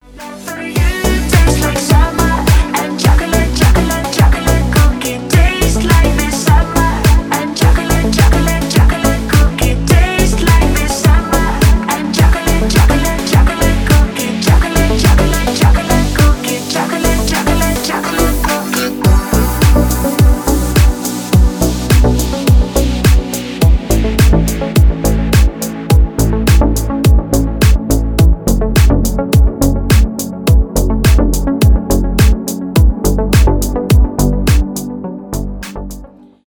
club house